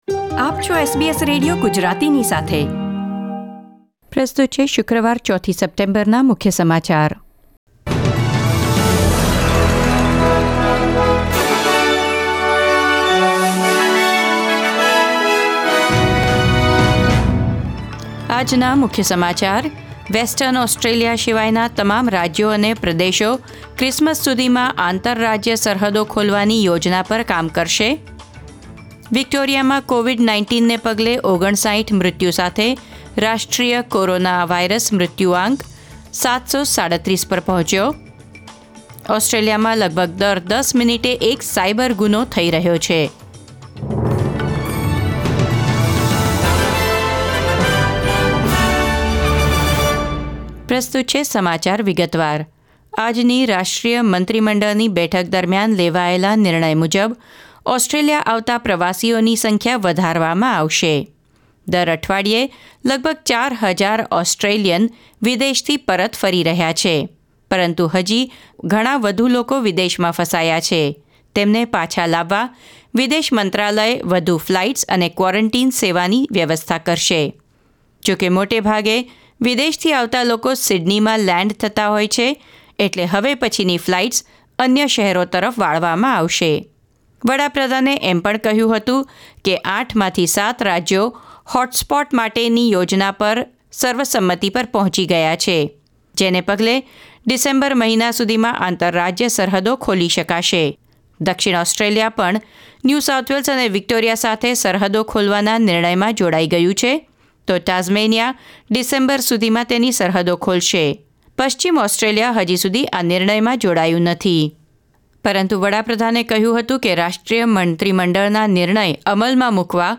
SBS Gujarati News Bulletin 4 September 2020